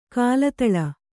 ♪ kālataḷa